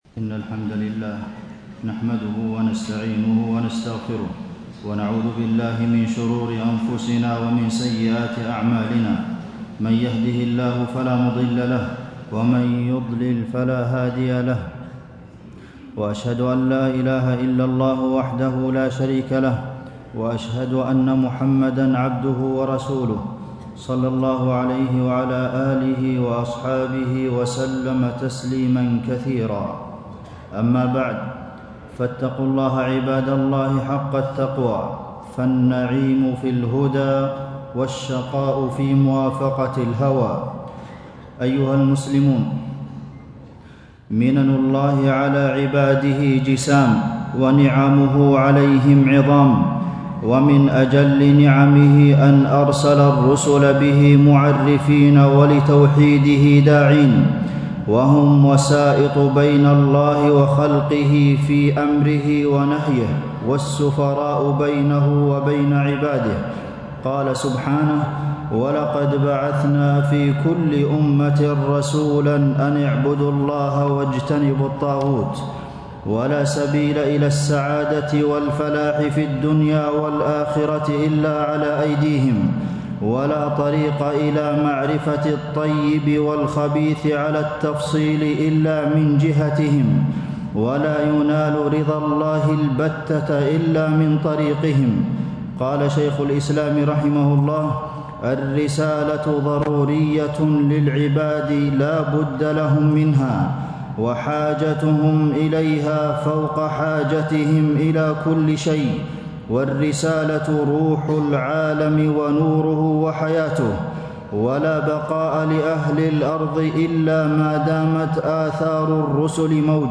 تاريخ النشر ٣ ربيع الثاني ١٤٣٦ هـ المكان: المسجد النبوي الشيخ: فضيلة الشيخ د. عبدالمحسن بن محمد القاسم فضيلة الشيخ د. عبدالمحسن بن محمد القاسم حقوق النبي صلى الله عليه وسلم